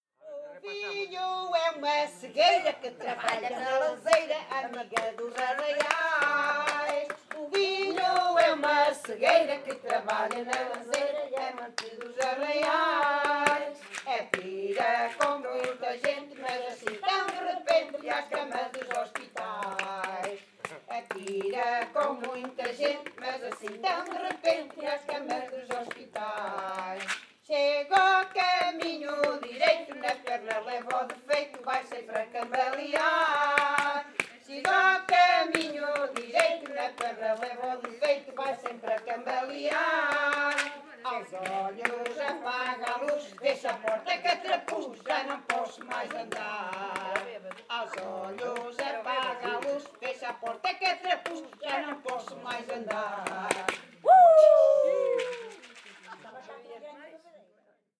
Grupo Etnográfico de Trajes e Cantares do Linho de Várzea de Calde
Várzea de Calde